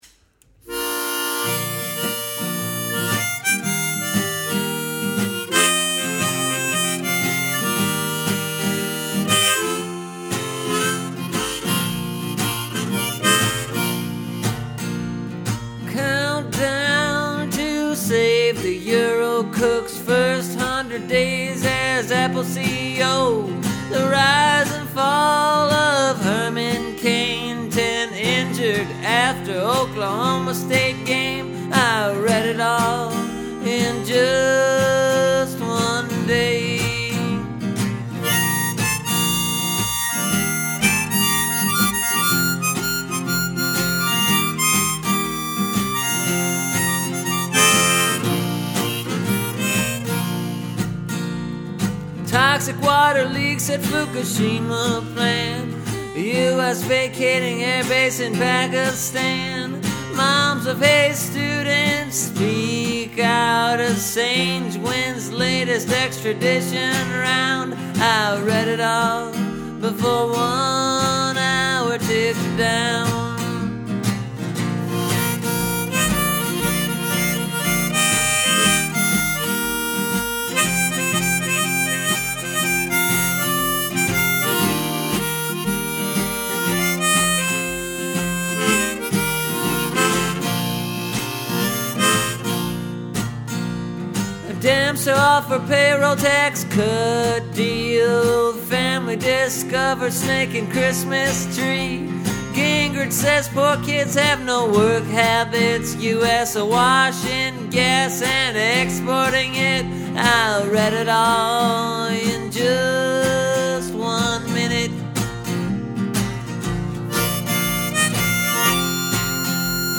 This is the collection of headlines for this week. It’s a rock-em-sock-em version.